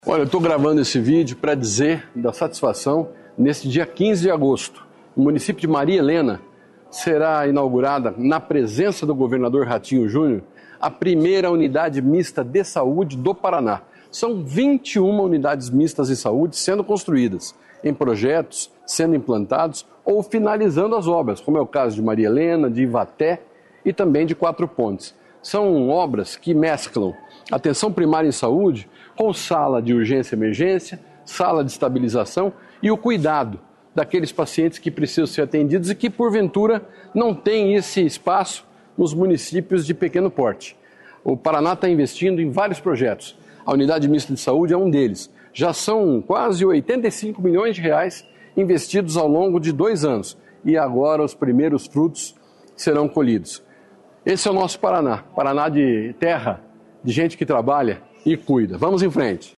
Sonora do secretário Estadual da Saúde, Beto Preto, sobre as 21 Unidades Mistas de Saúde sendo construídas pelo Paraná